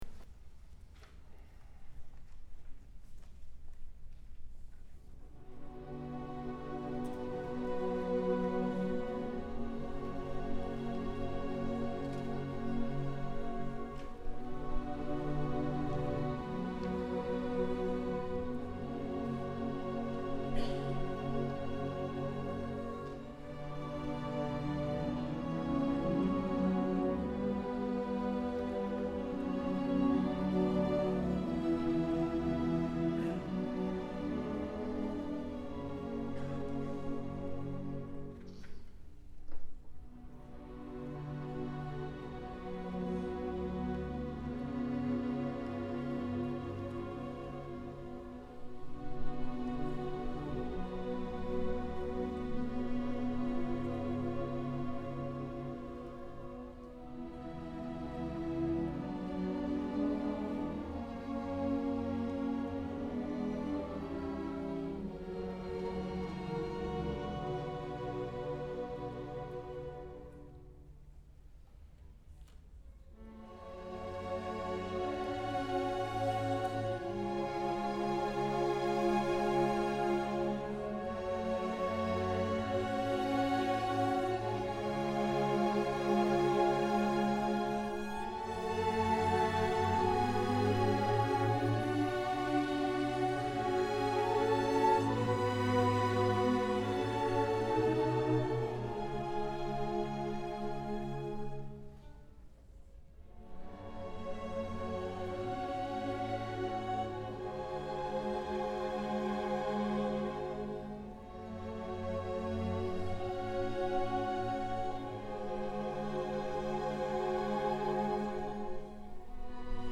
Santa Barbara City College Symphony Concert, Fall 2008
SBCC Symphony Concert Recording - November 23, 2008